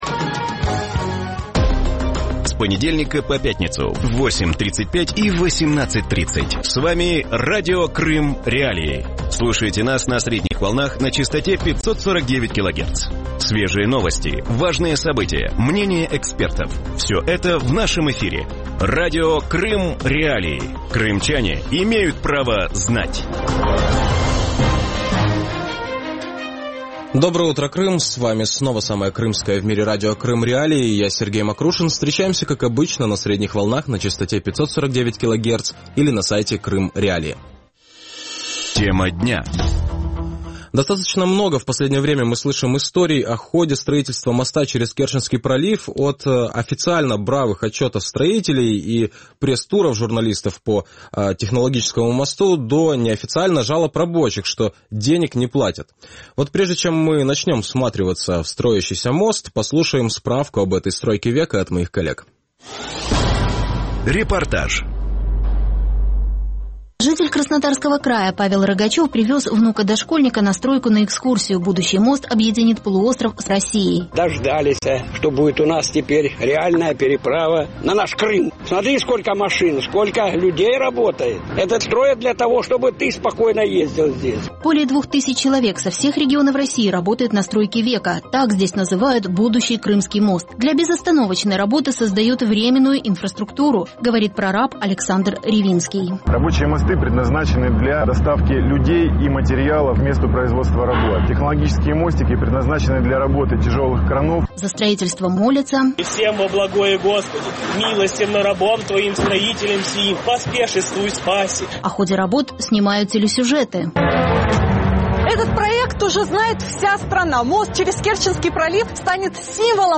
Вранці в ефірі Радіо Крим.Реалії говорять про будівництво керченського мосту. Наскільки реально побудувати цю споруду без ризику для його подальшої експлуатації? Чи можна провести усі роботи без розкрадання виділених коштів?